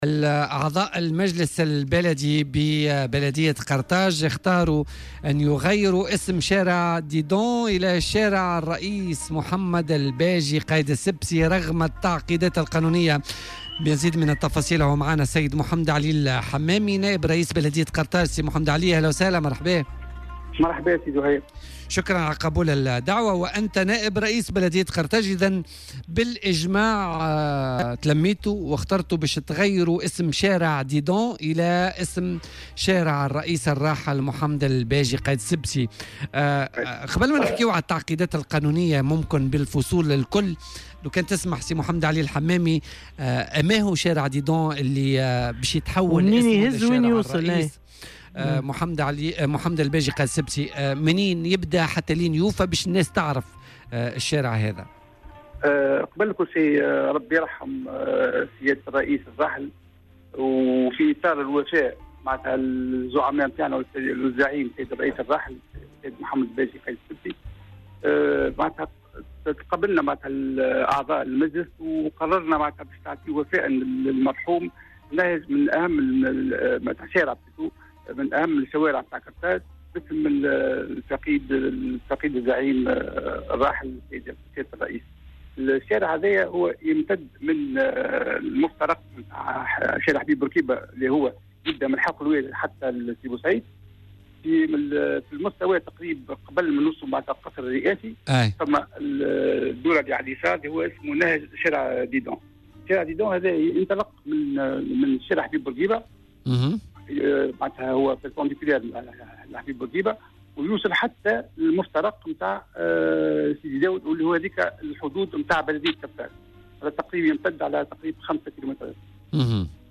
أكد نائب رئيس بلدية قرطاج محمد علي الحمامي، اليوم الاثنين أنه تقرر بالإجماع تغيير اسم شارع ديدون بضاحية قرطاج ليحمل مستقبلا اسم الرئيس الراحل محمد الباجي قايد السبسي. وأوضح في مداخلة هاتفية مع "بوليتيكا" على "الجوهرة أف أم" أن هذا الشارع من أهم شوارع قرطاج ويمتد من مفترق شارع الحبيب بورقيبة الذي يبدأ من حلق الوادي إلى سيدي بوسعيد، وقبل وصول قصر قرطاج الرئاسي نجد شارع ديدون متقاطعا من جهة اليسار مع شارع الحبيب بورقيبة ليتواصل الى غاية مفترق سيدي داود الذي يمثل حدود بلدية قرطاج وعلى مسافة 5 كيلومترات.